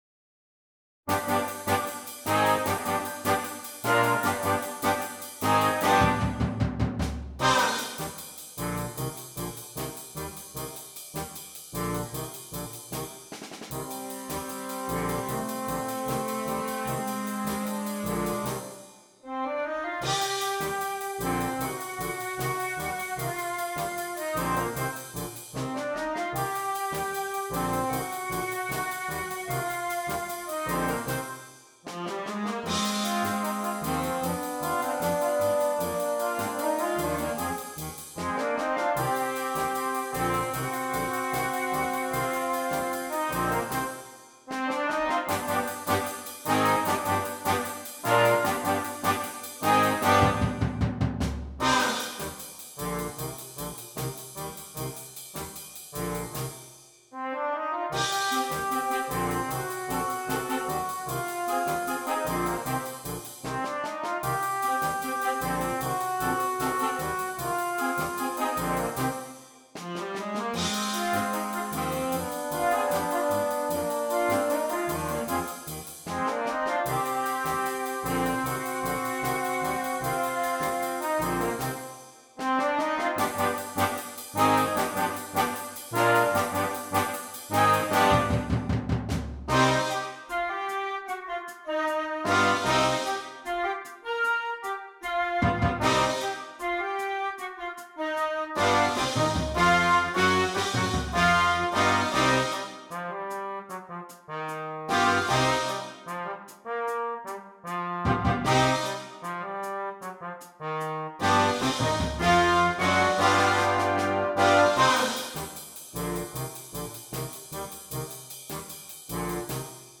Flexible Band